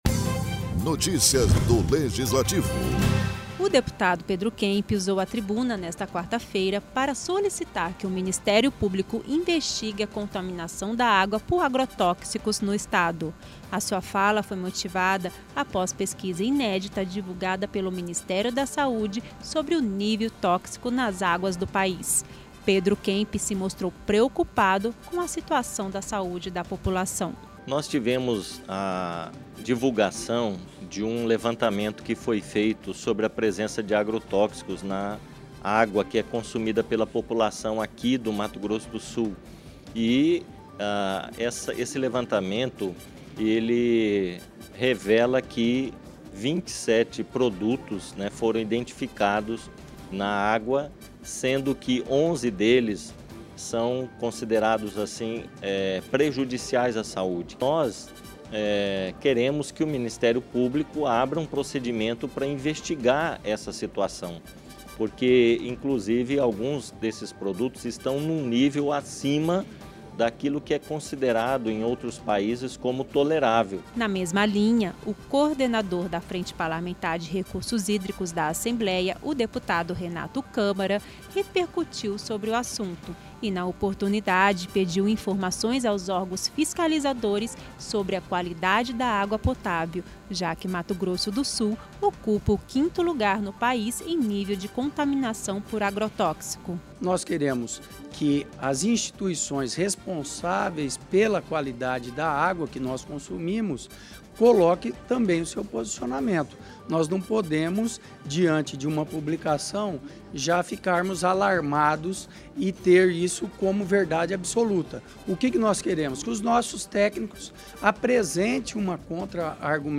Em sessão ordinária dessa quarta-feira (17), deputados estaduais usaram a tribuna para questionar a fiscalização dos órgãos sobre a qualidade da água em Mato Grosso do Sul .